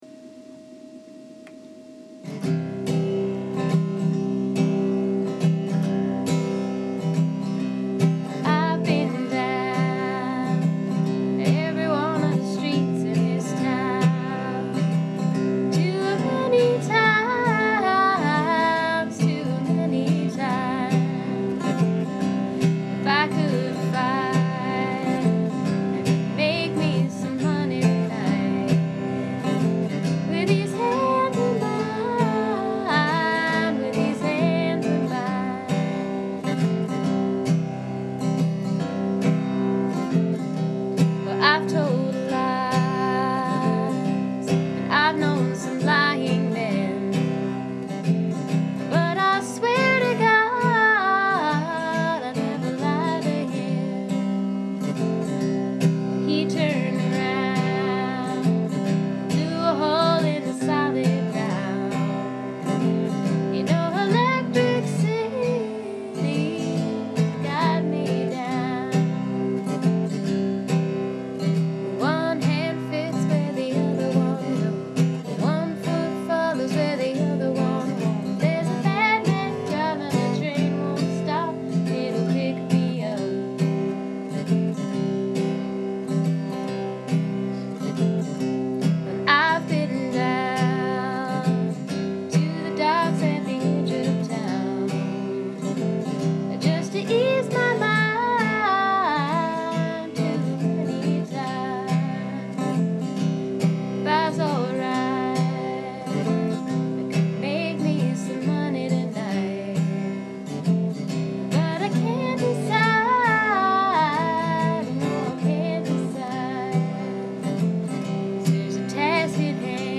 Its in C.
This song in particular has a timeless quality such that I feel as if I've heard it before, possibly before I was born.
The changes fall on a lot of offbeats on this tune.
• If I could change anything about this recording, I'd throw in a little dynamic variation.